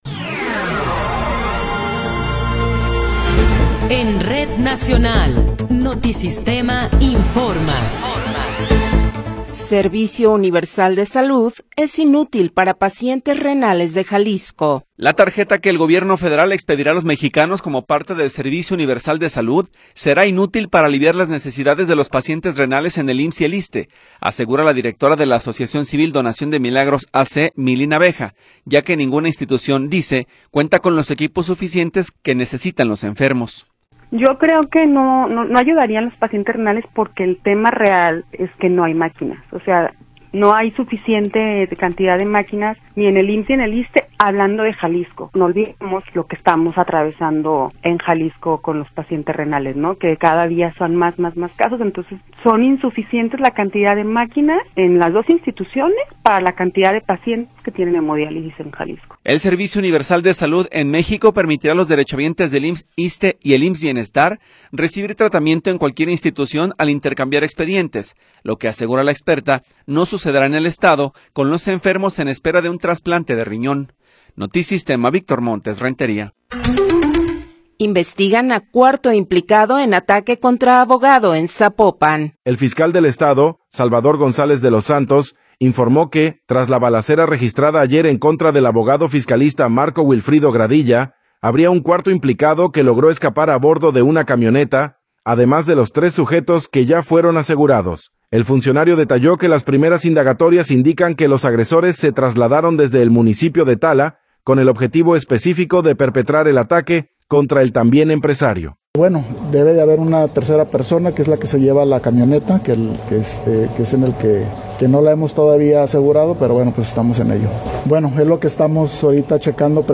Noticiero 15 hrs. – 28 de Enero de 2026
Resumen informativo Notisistema, la mejor y más completa información cada hora en la hora.